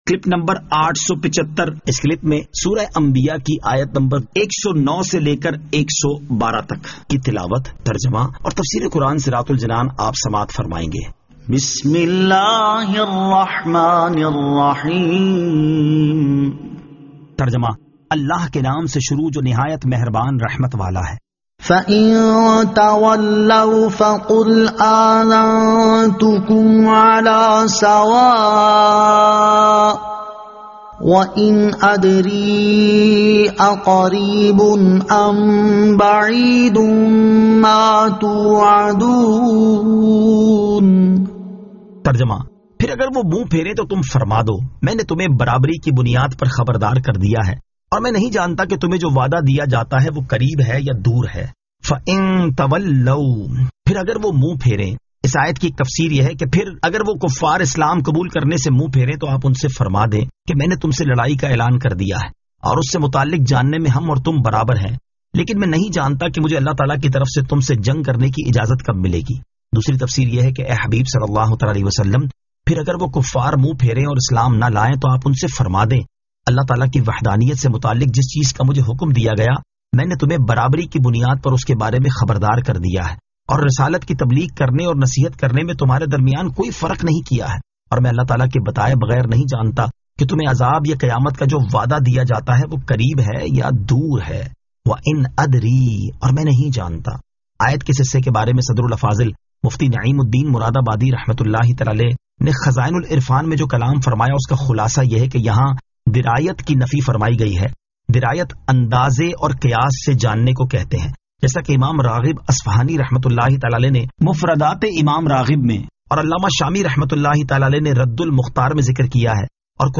Surah Al-Anbiya 109 To 112 Tilawat , Tarjama , Tafseer